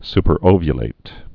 (spər-ōvyə-lāt, -ŏvyə-)